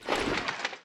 equip_gold1.ogg